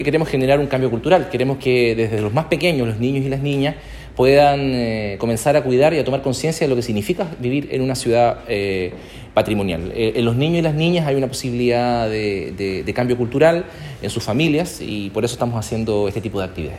Alcalde-Sharp-por-actividades-de-celebracion-20-anos.mp3